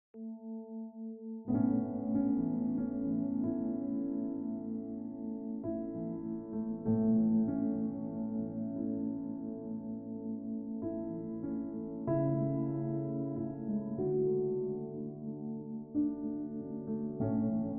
888 Hz — The Frequency sound effects free download